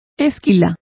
Complete with pronunciation of the translation of shearings.